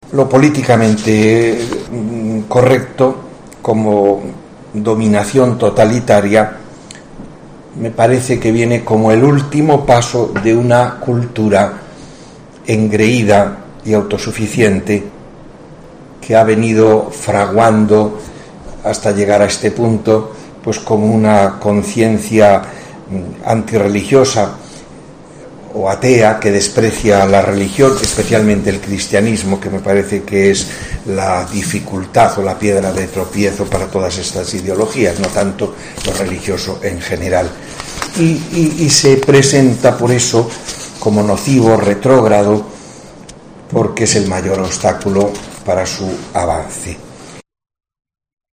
Rafael Zornoza, Obispo de la Diócesis de Cádiz y Ceuta, en el acto inaugural